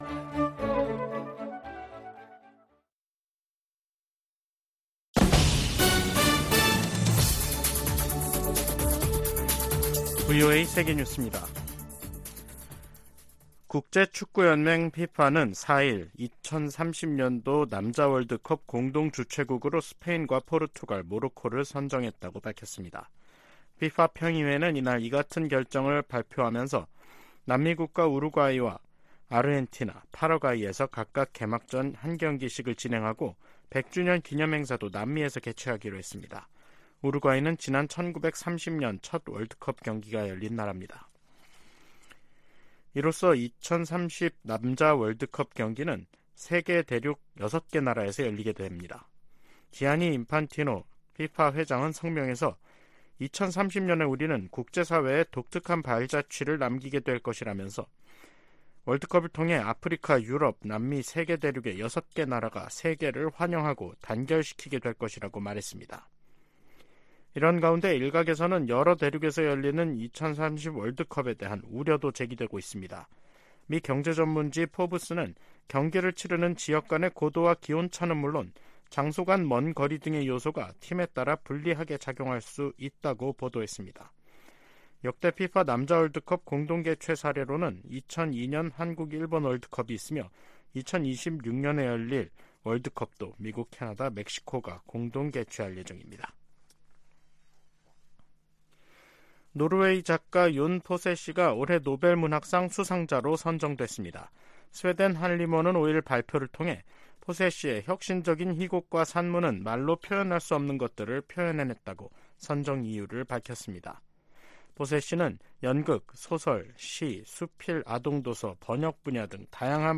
VOA 한국어 간판 뉴스 프로그램 '뉴스 투데이', 2023년 10월 5일 3부 방송입니다. 로이드 오스틴 미 국방장관과 기하라 미노루 일본 방위상이 북한의 도발과 중국의 강압, 러시아의 전쟁을 미-일 공통 도전으로 규정했습니다. 미국 국무부가 제재 대상 북한 유조선이 중국 영해에 출몰하는 데 대해, 사실이라면 우려한다는 입장을 밝혔습니다. 한국 헌법재판소가 대북전단금지법에 위헌 결정을 내리면서 민간단체들이 살포 재개 움직임을 보이고 있습니다.